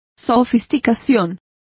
Complete with pronunciation of the translation of sophistication.